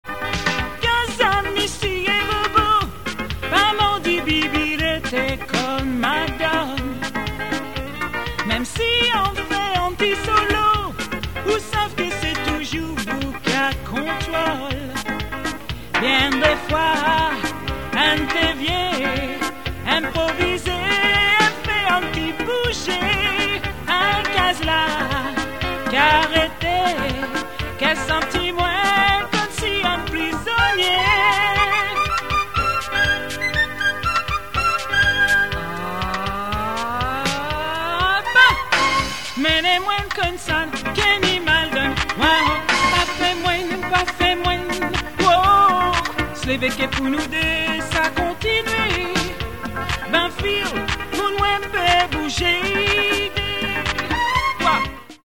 EXTRAIT DISCO